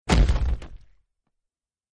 land.mp3